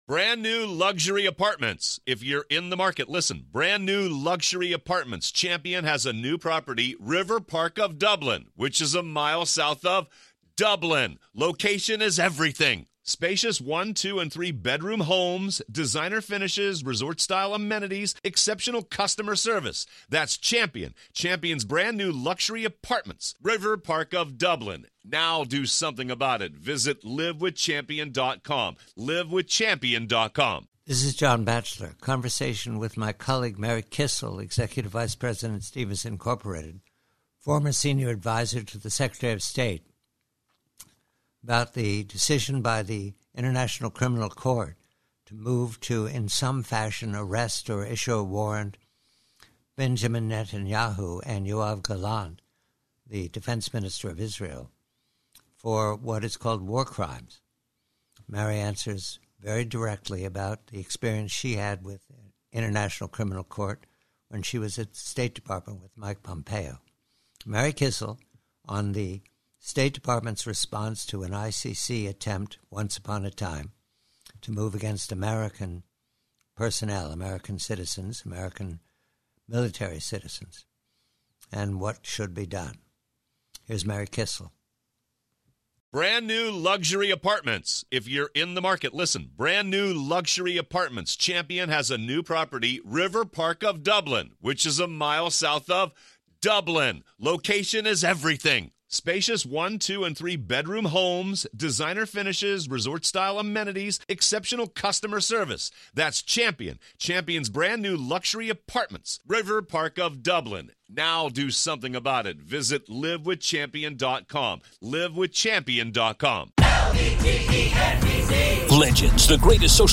PREVIEW: ICC: Conversation with colleague Mary Kissel re the ICC talk of an arrest of PM Netanyahu and Defence Minister Gallant -- and Mary Kissel quickly reminds that the ICC attempted similar lawfare against America with regard to Afghanistan and was me